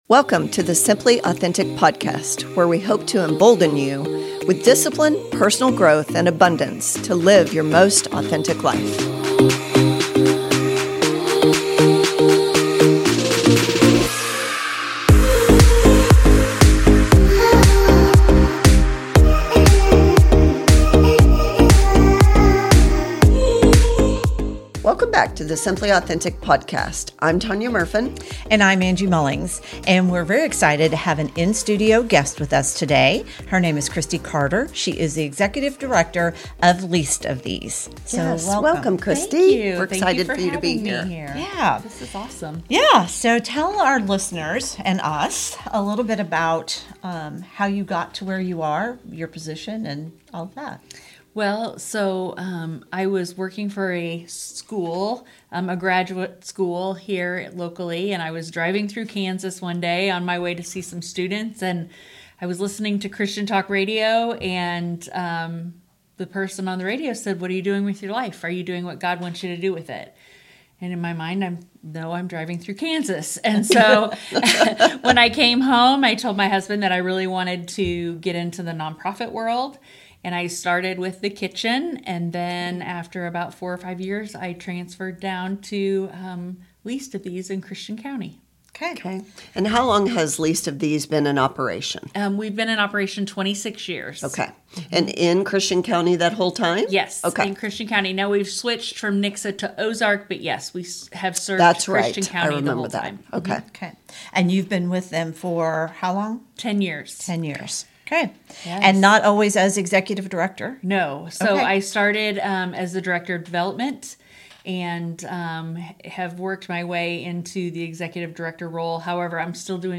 Join us for an inspiring conversation about community, compassion, and the year-round fight against hunger.